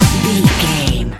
Aeolian/Minor
Fast
drum machine
synthesiser
electric piano
conga